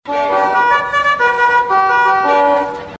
• CALL TO POST HORN.mp3
A military horn, calling troopers to arms.
call_to_post_horn_epz.wav